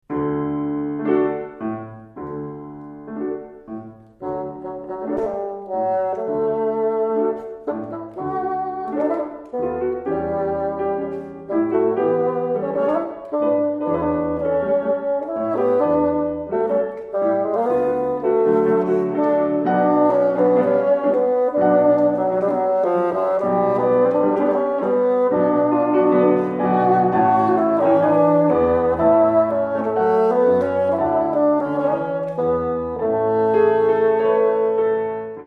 癒しの音楽